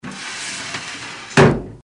Звук захлопывающейся аптечки